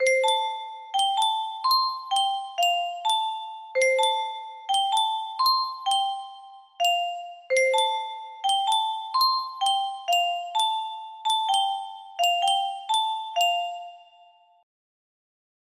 BPM 64